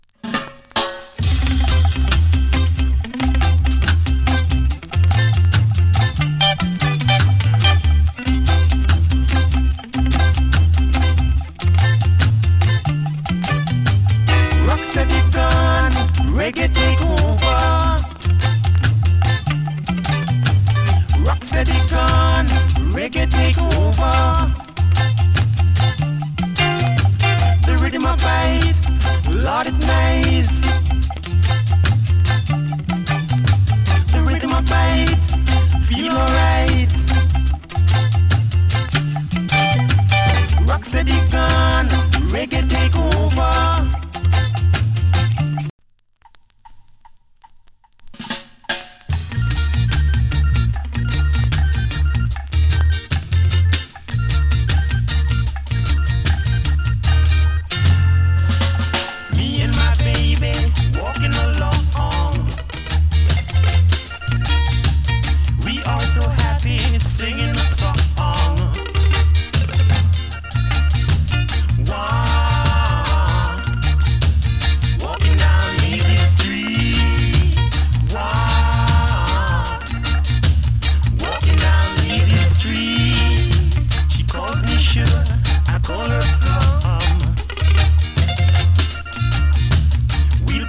A：VG(OK) / B：VG(OK) NOC. ＊スリキズ有り。チリ、パチノイズ少々有り。わずかに BEND 有り。